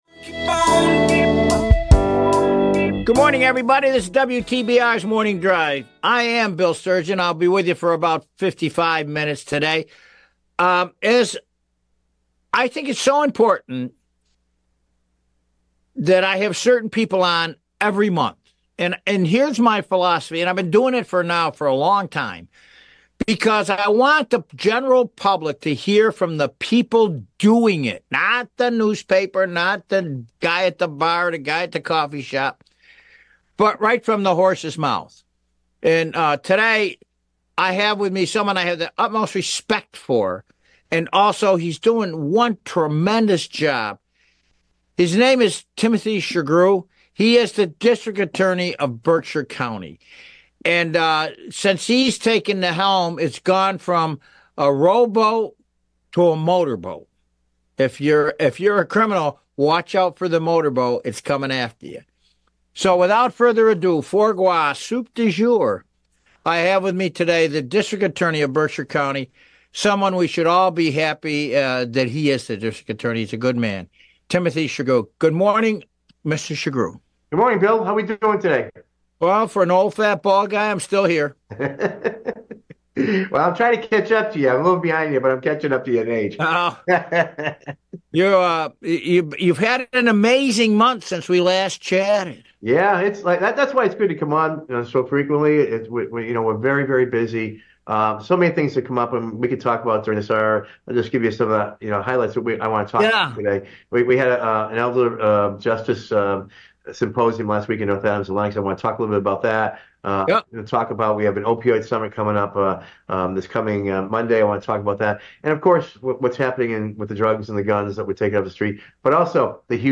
talks with Berkshire District Attorney Timothy Shugrue.